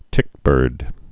(tĭkbûrd)